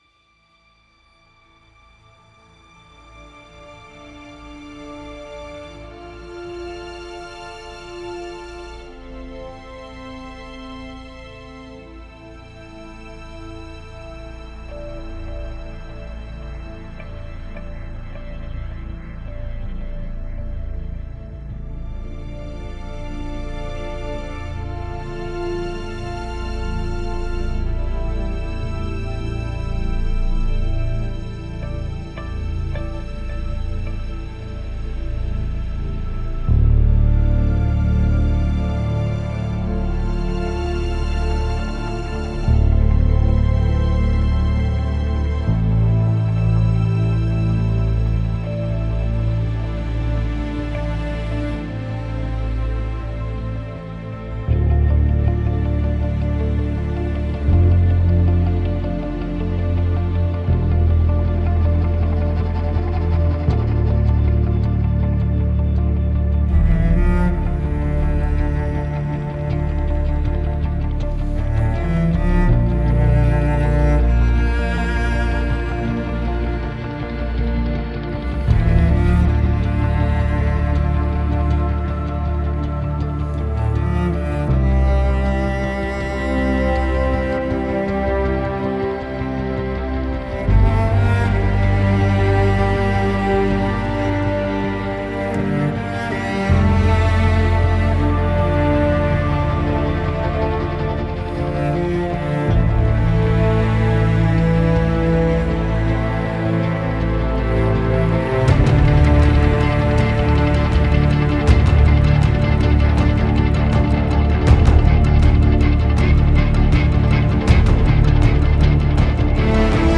ژانر: بی کلام
🎵 موزیک بی کلام، برای ادیت ویدیوهاتون و تولید محتوا هاتون👆🎶